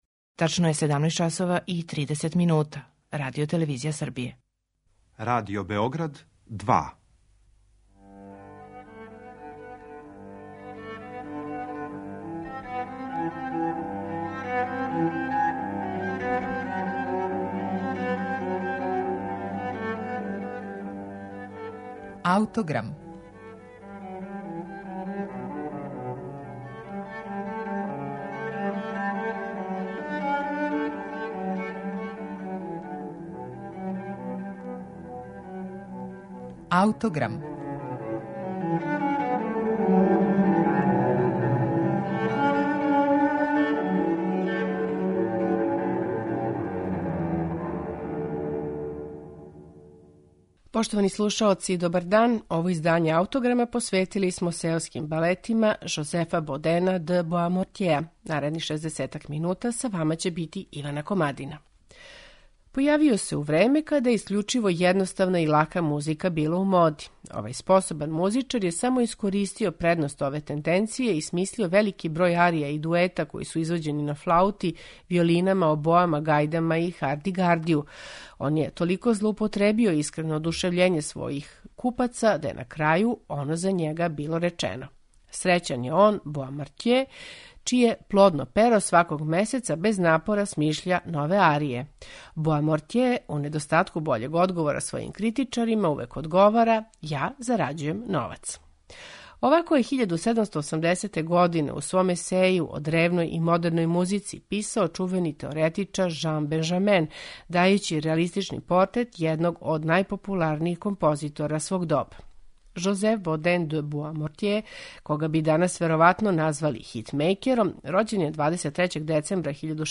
Посебно место у његовом опусу имају „Сеоски балети", најважније композиције које је компоновао за тада популарне инструменте мизету и харди-гарди. У данашњем АУТОГРАМУ представићемо четири Боамортјеова „Сеоска балета", у интерпретацији анасамбла Le Concert Spirituel, под управом Ервеа Никеа.